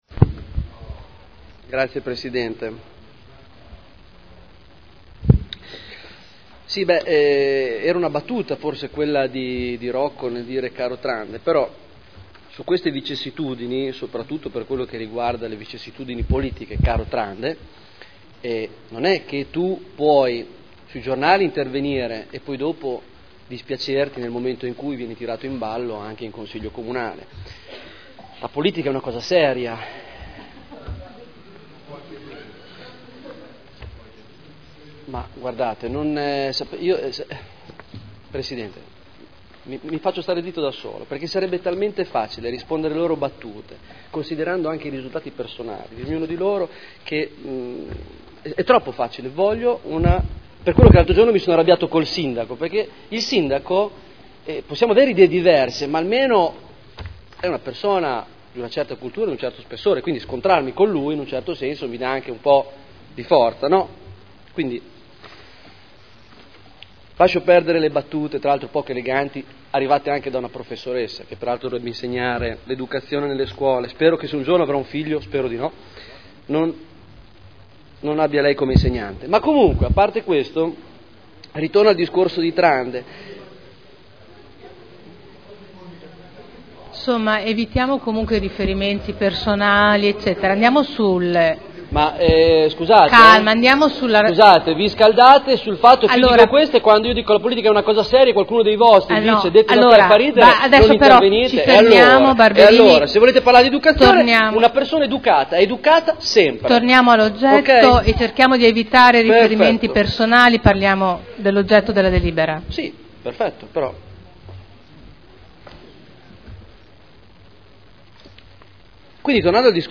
Seduta del 12 dicembre Commissione consiliare permanente Affari Istituzionali – Modifica Dibattito